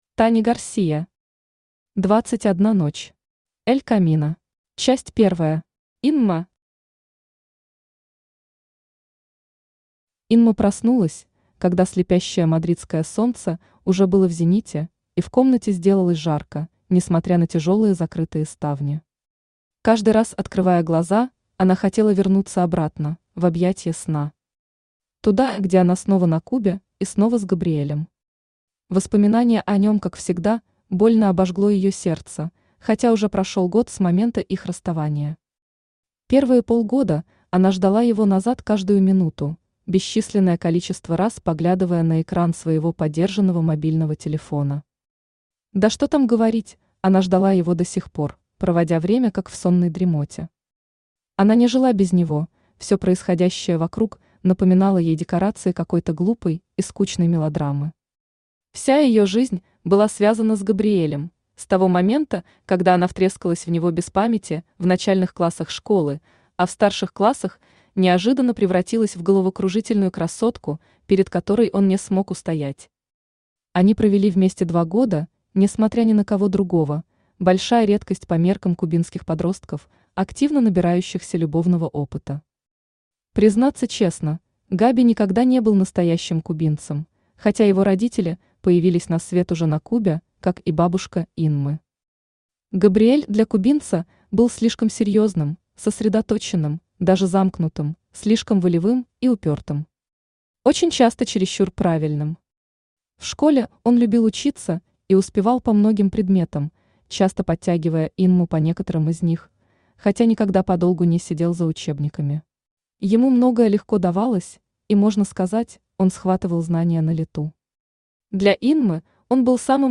Аудиокнига Двадцать одна ночь. Эль Камино | Библиотека аудиокниг
Эль Камино Автор Таня Гарсия Читает аудиокнигу Авточтец ЛитРес.